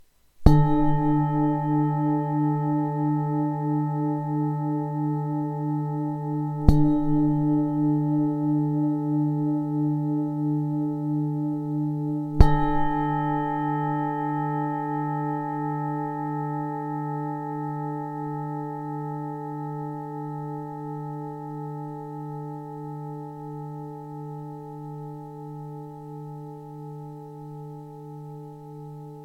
Zdobená tibetská mísa D3 23cm
Nahrávka mísy úderovou paličkou:
Jde o ručně tepanou tibetskou zpívající mísu dovezenou z Nepálu.
Tahle mísa zní v tónu sakrální čakry.